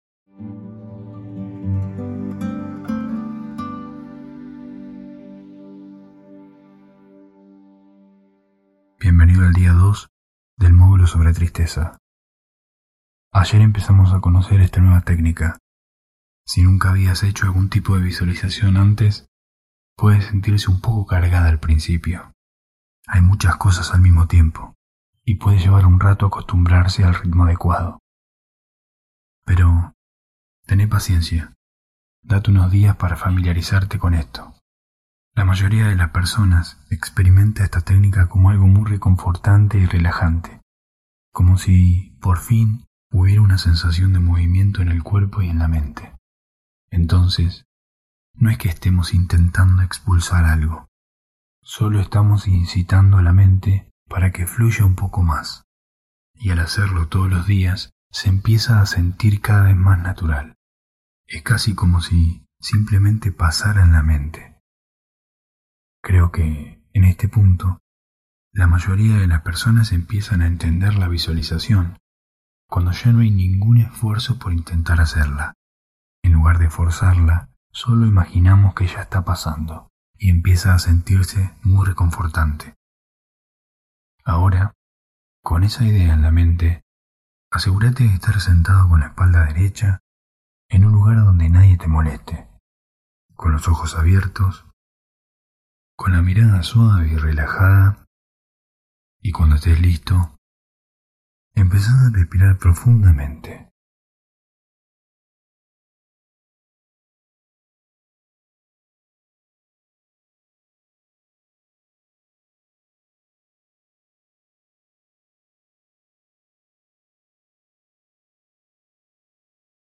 Cambiá la forma de relacionarte con la tristeza. Día 2 [Audio 8D. Mejor con auriculares] Hosted on Acast.